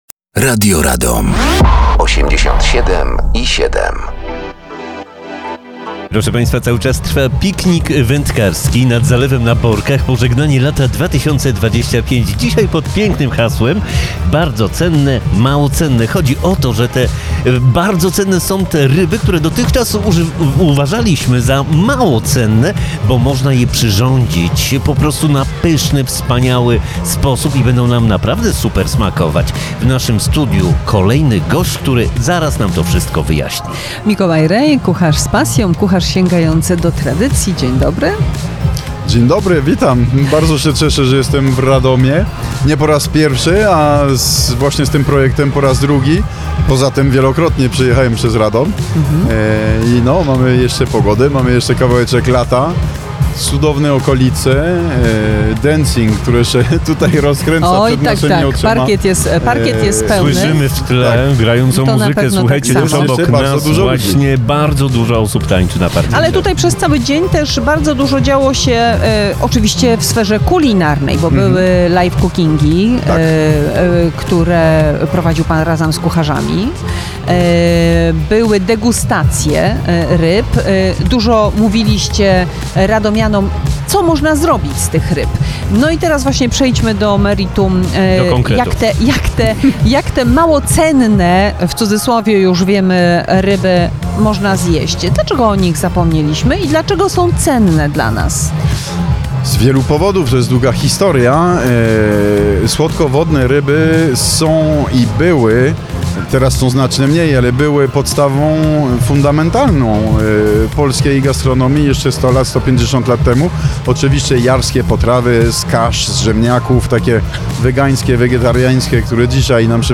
Mobilne Studio Radi Radom na Zalewem na Borkach podczas pikniku wędkarskiego Pożegnanie Lata 2025.